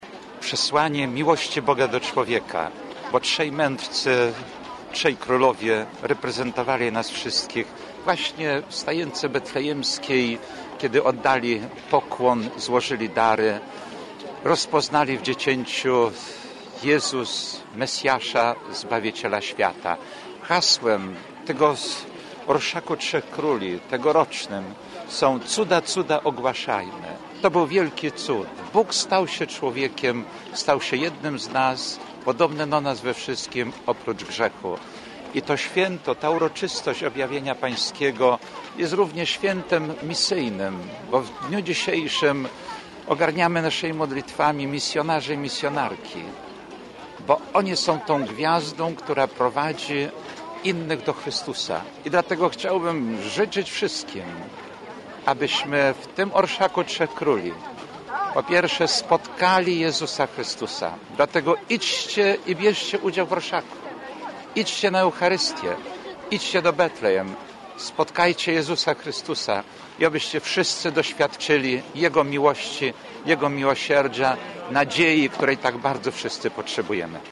– Orszak Trzech Króli to okazja do integracji międzypokoleniowej i wspólnej manifestacji wiary – przyznawali uczestnicy pochodu.
– Przy okazji tych, największych na świecie, ulicznych jasełek warto przypomnieć również o przesłaniu tego wydarzenia – mówi biskup ełcki, ks. Jerzy Mazur.